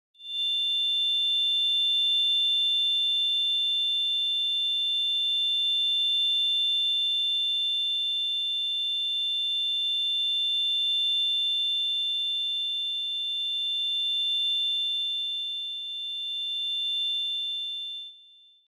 Sonneries » Sons - Effets Sonores » Acouphène